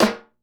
Snares
DrSnare41.wav